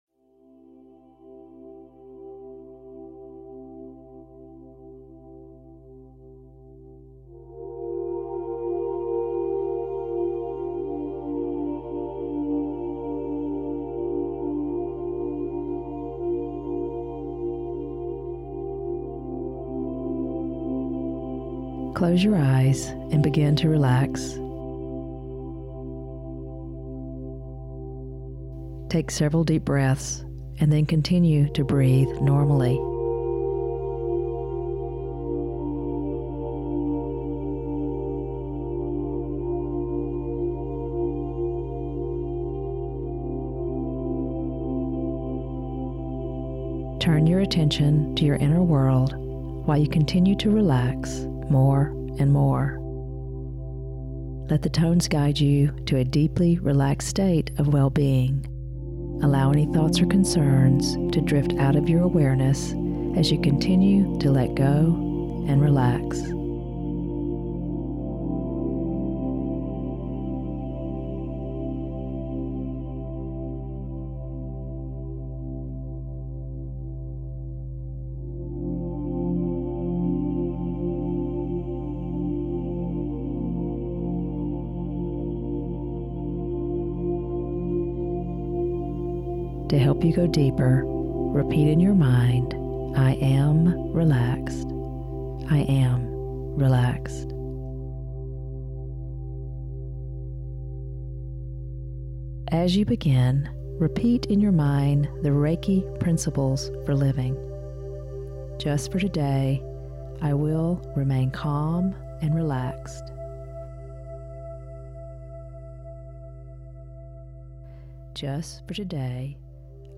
This extended version meditation will be instrumental in helping you return to a more balanced energy body.